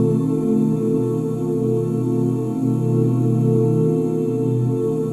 OOHB FLAT5.wav